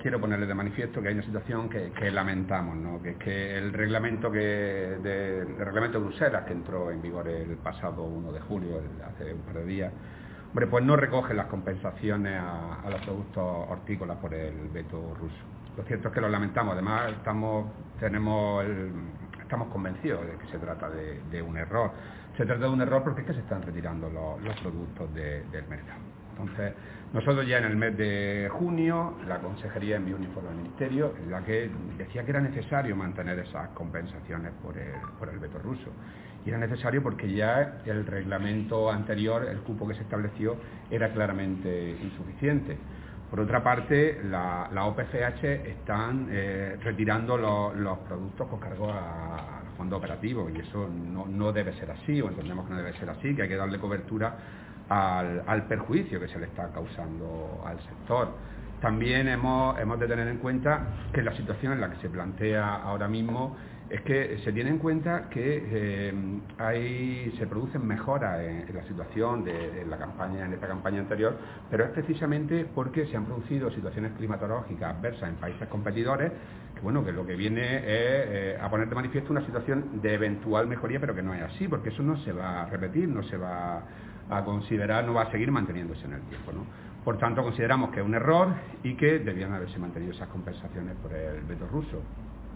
Declaraciones de Rodrigo Sánchez sobre la exclusión de las hortalizas de las ayudas por el veto ruso